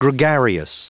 Prononciation du mot gregarious en anglais (fichier audio)
Prononciation du mot : gregarious
gregarious.wav